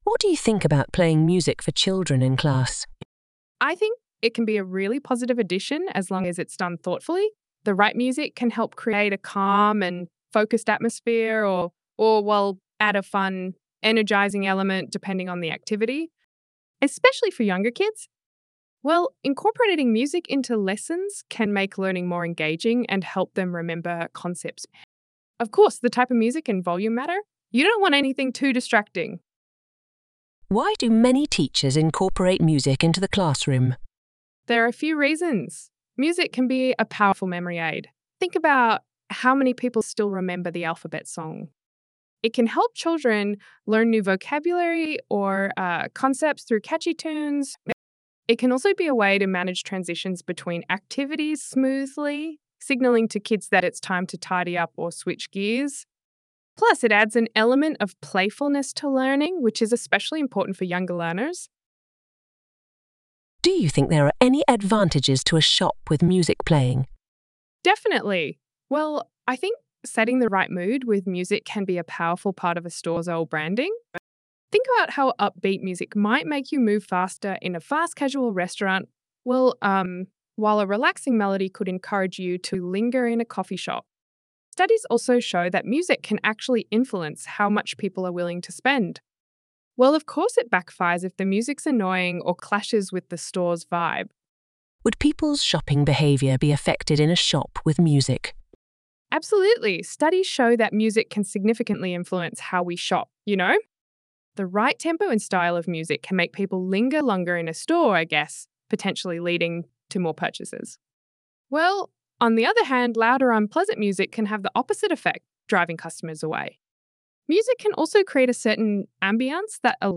Trong bài viết này, Mc IELTS chia sẻ câu trả lời mẫu band 8.0+ từ cựu giám khảo IELTS, kèm theo các câu hỏi mở rộng và bản audio từ giáo viên bản xứ để bạn luyện phát âm, ngữ điệu và tốc độ nói tự nhiên.